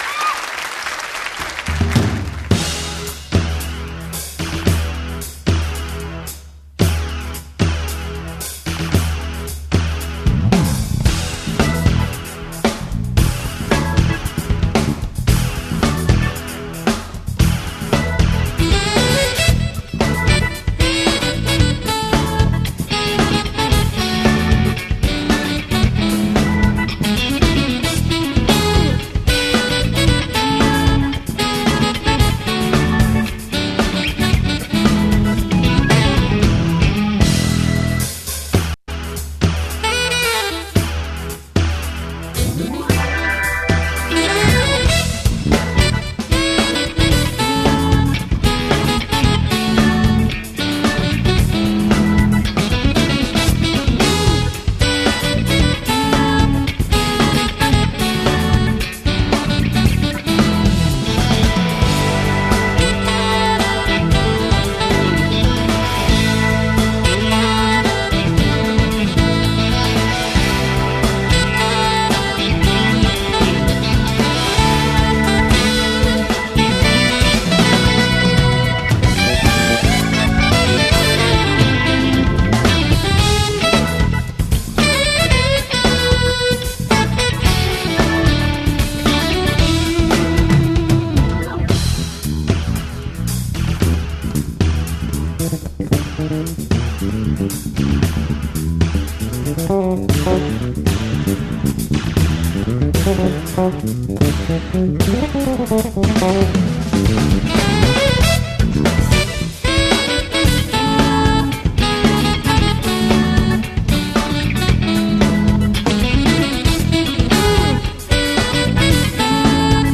[session live]
베이스